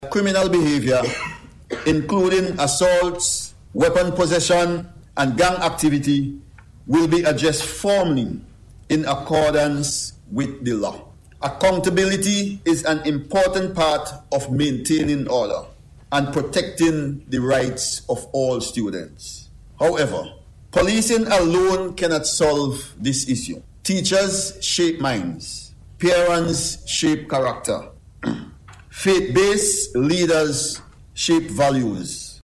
Addressing the opening of today’s Consultation on School Violence, Deputy Commissioner Joseph says violence in schools not only traumatizes teachers but also places students at risk, underscoring the urgent need for action.